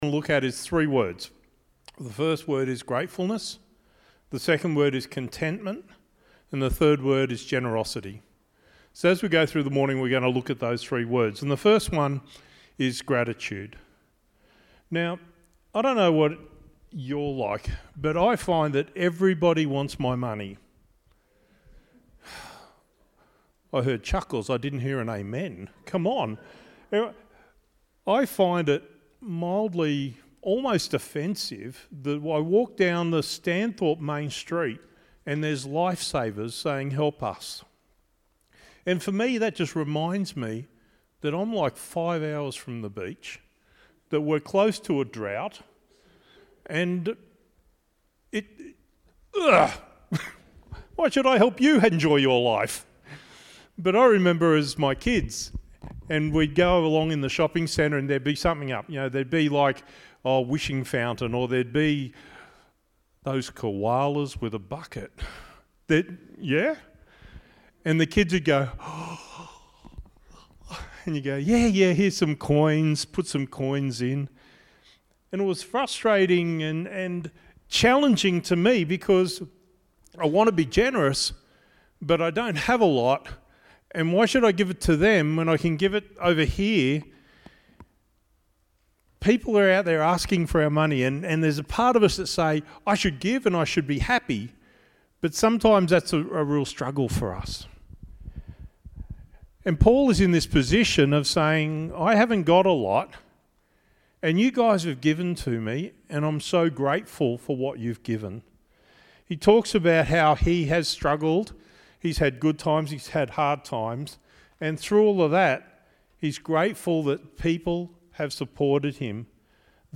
Songs sung during the message were Give Thanks and 10,000 Reasons. Closed service by singing Build your Kingdom Here.